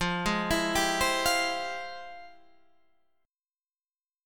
F Minor Major 9th